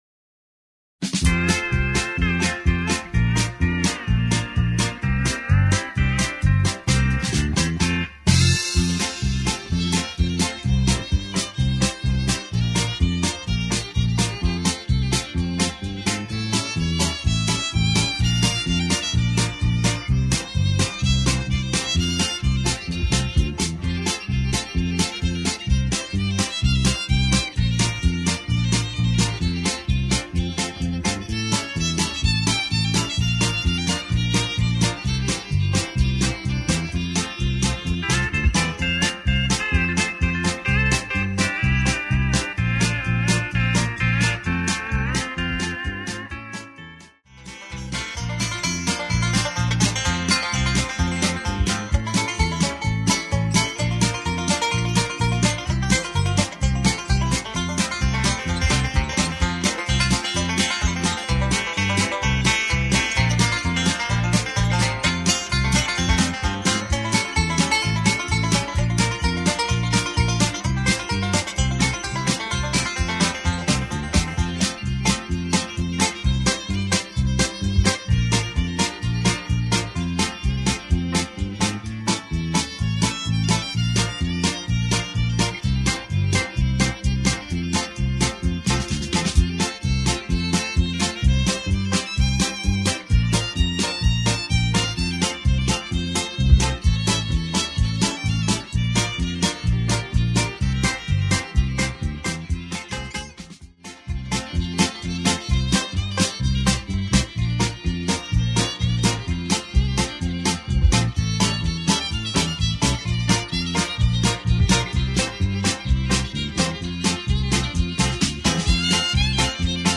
(Key of A)